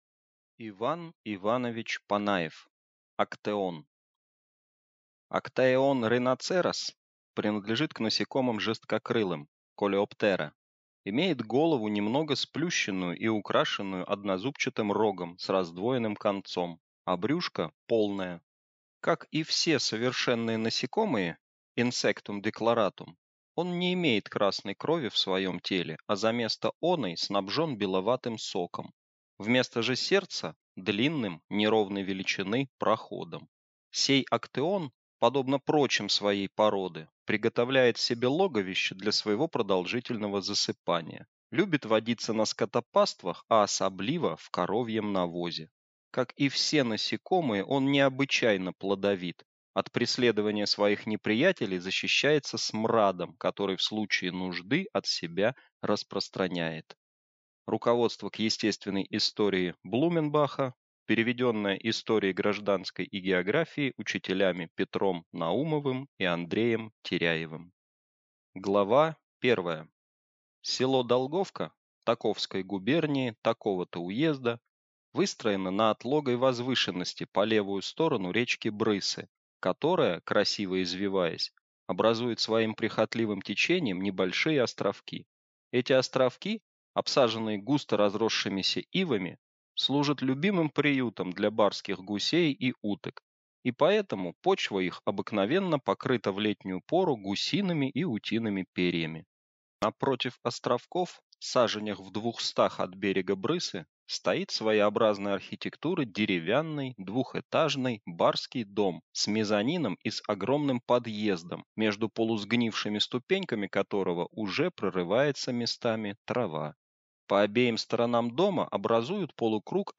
Аудиокнига Актеон | Библиотека аудиокниг